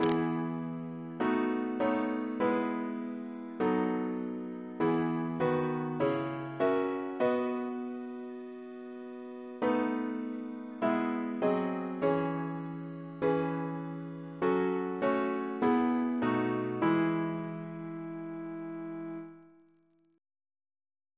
CCP: Chant sampler
Double chant in E Composer: Thomas W. Hanforth (1867-1948) Reference psalters: RSCM: 63